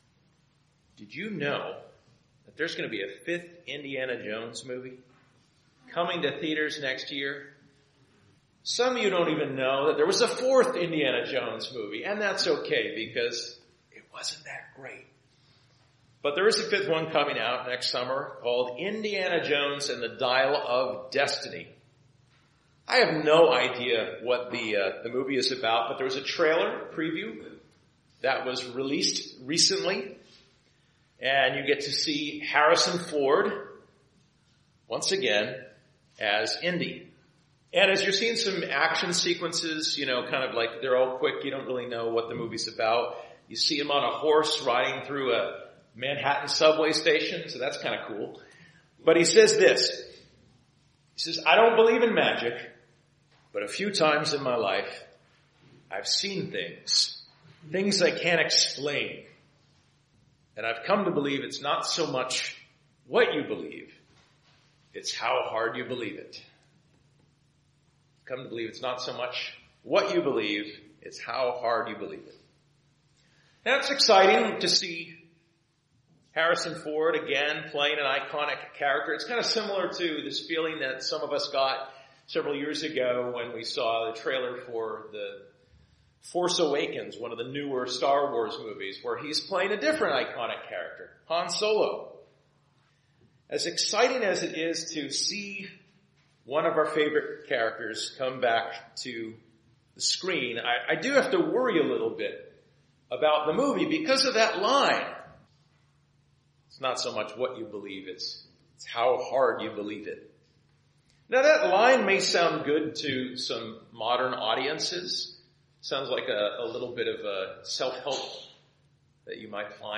Join us for a brief sermon series on the book of Jude. This book of the New Testament teaches us to contend for the faith ones delivered to the saints, to be wary of false teachers, and to continue in the faith through mutual upbuilding and prayer.